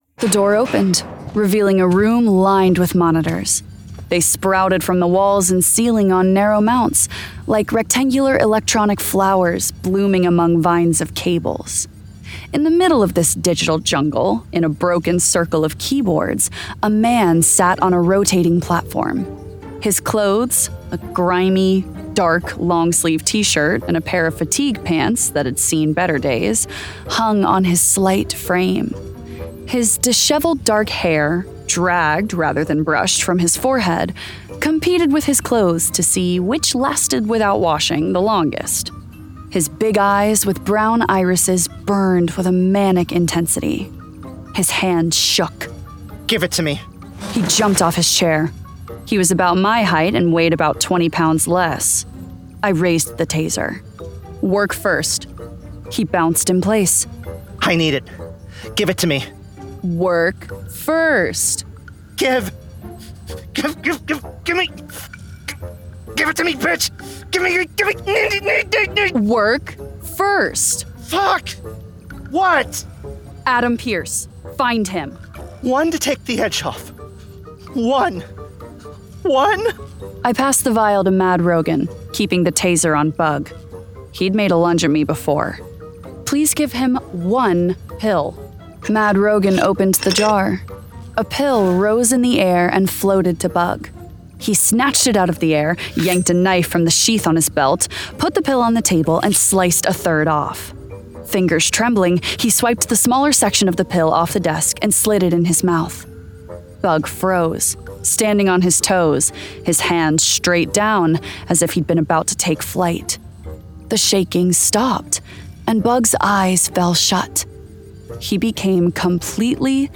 Full Cast. Cinematic Music. Sound Effects.
Genre: Fantasy Romance
Adapted from the novel and produced with a full cast of actors, immersive sound effects and cinematic music!